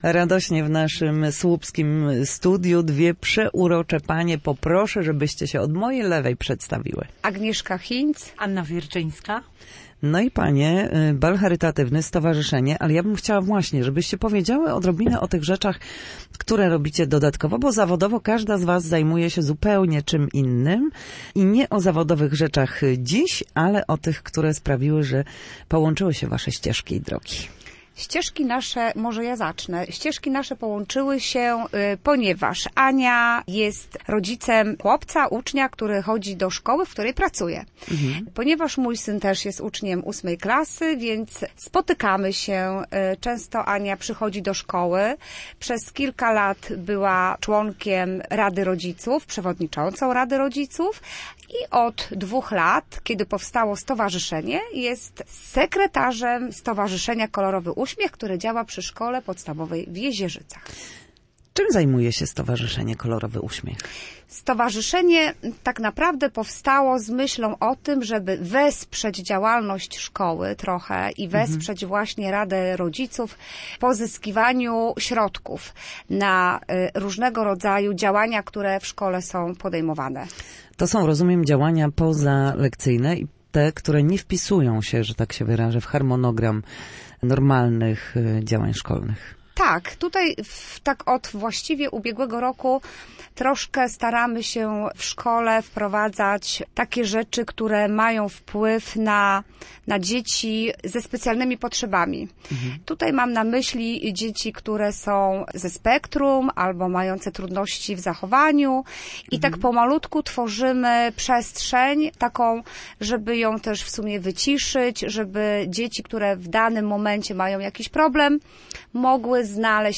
Na naszej antenie mówiły o zbliżającym się balu charytatywnym oraz wsparciu dla uczniów Szkoły Podstawowej w Jezierzycach.